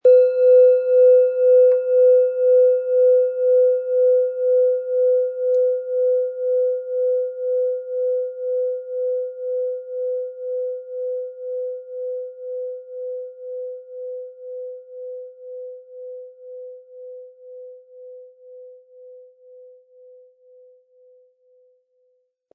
• Tiefster Ton: Chiron
• Höchster Ton: Mond
PlanetentöneSonne & Chiron & Mond (Höchster Ton)
MaterialBronze